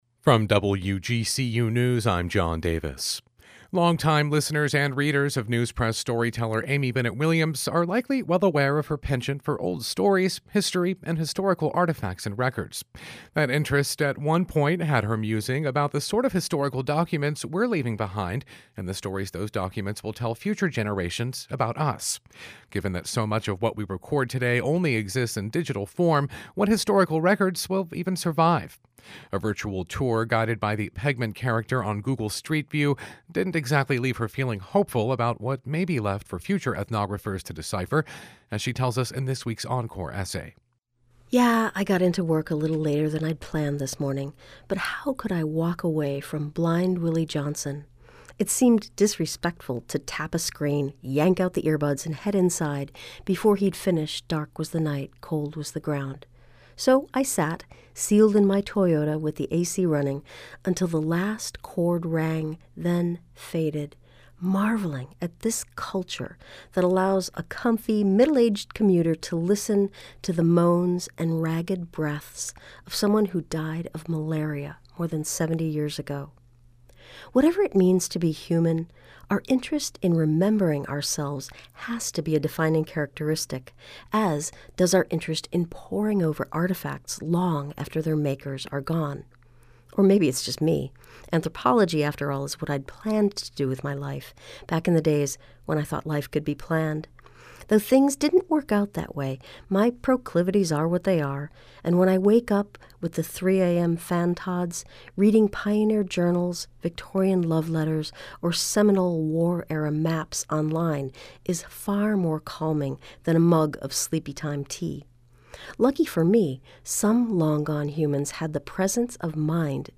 A virtual tour guided by the pegman character on Google Street View didn't exactly leave her feeling hopeful about what may be left for future ethnographers to decipher, as she tells us in this week's encore essay.